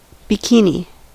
Ääntäminen
IPA : /bᵻˈkiːni/